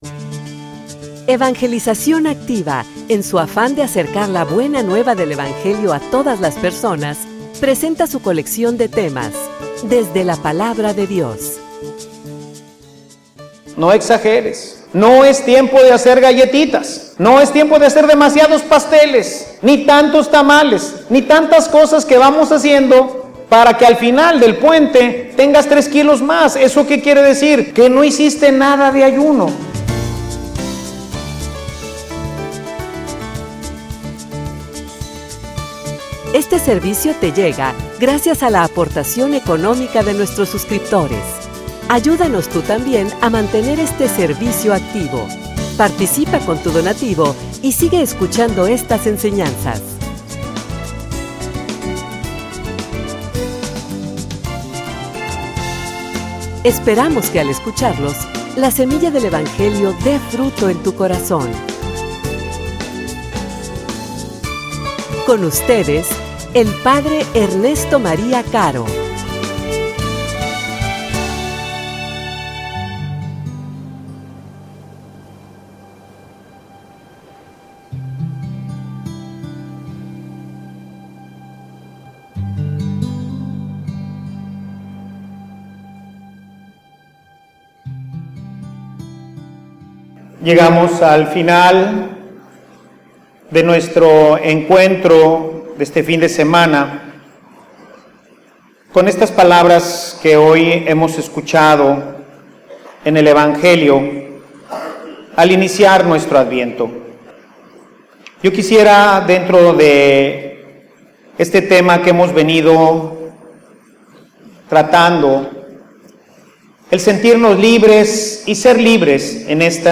homilia_Un_adviento_diferente.mp3